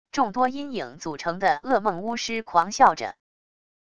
众多阴影组成的恶梦巫师狂笑着wav音频